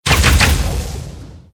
archer_skill_lifttwinshot_03_swing_a.ogg